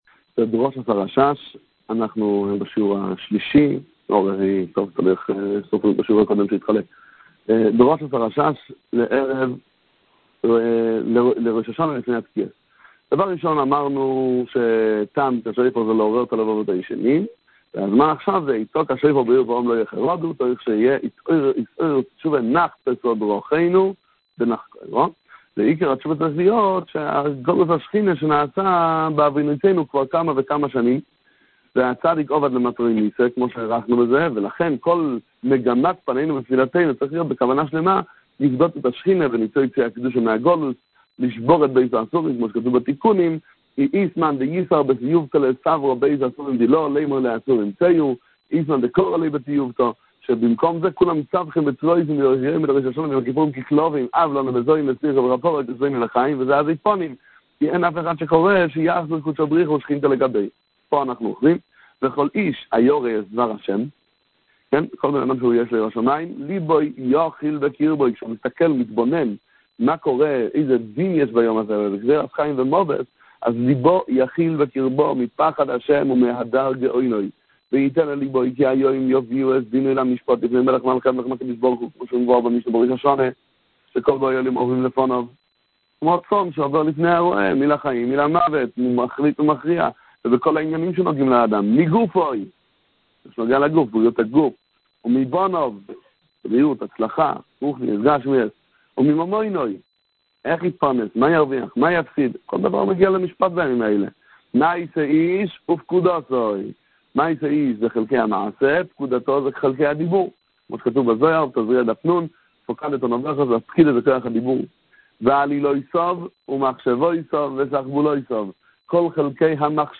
שיעור_3_דרשת_הרשש_לפני_התקיעות.mp3